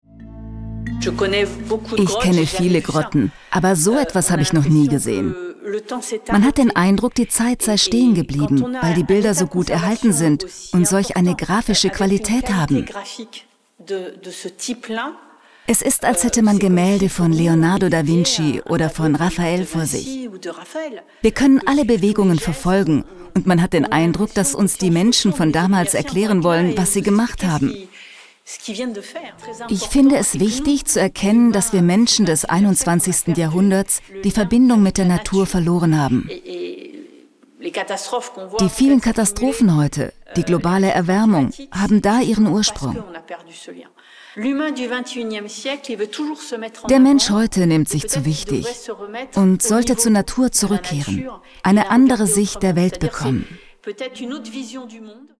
Overlay, Doku